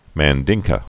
(măn-dĭngkə)